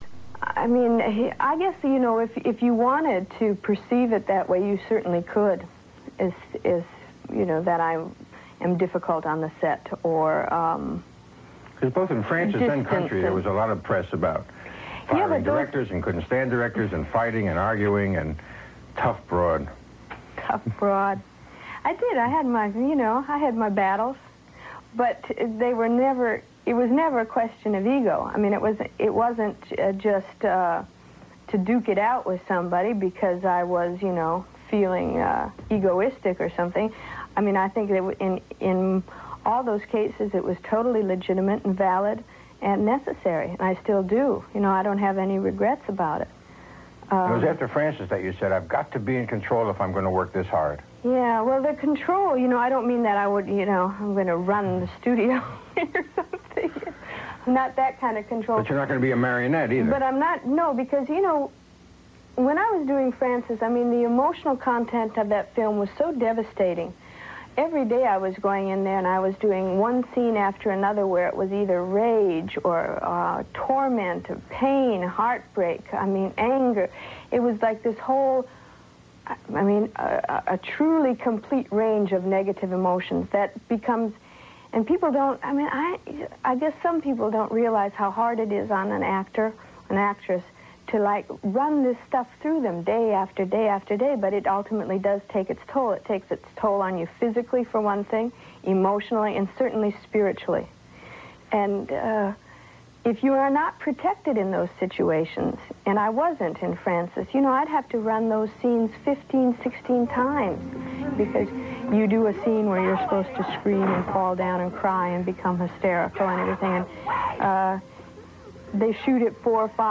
Click above to hear a RealAudio clip of Jessica Lange discussing why she's perceived as difficult on the set and the range of negative emotions it took making the film "Frances" as she talks with NBC's Gene Shalit in 1985